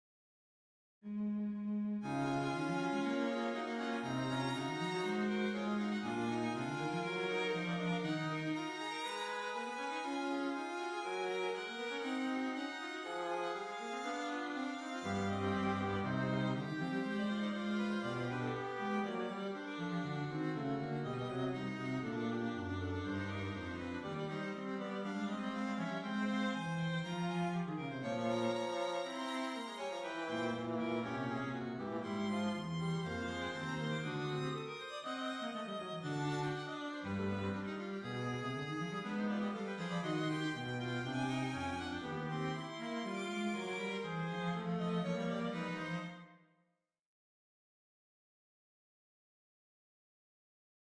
Très beau passage basé sur le sujet exposé en strettes dans les tonalités éloignées de ré bémol puis mi bémol mineur mes 47 et 48.
Ce passage est très surprenant du point de vue de l’harmonie du fait des fausses relations engendrées par la superposition des voix.
Noter dans ce passage l’importance de l’élément rythmique en notés répétées de C qui va se retrouver exposé à toutes les voix.